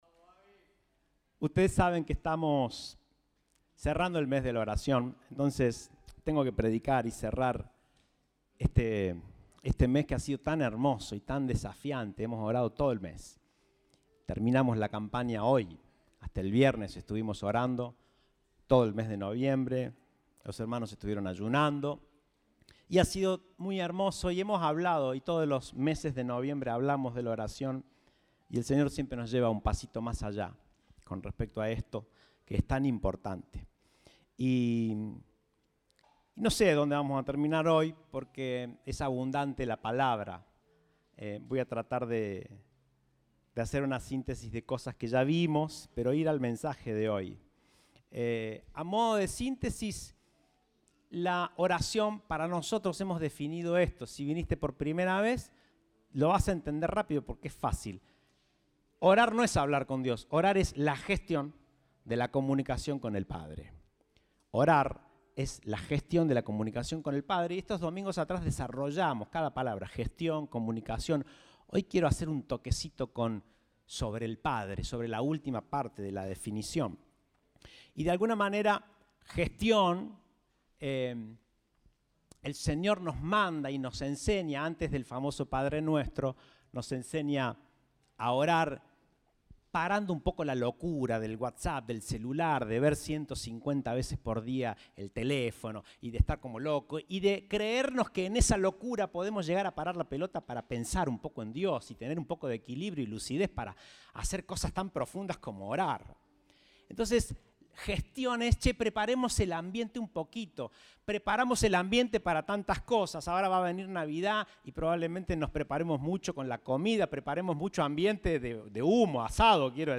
Compartimos el mensaje del Domingo 4 de Diciembre de 2022.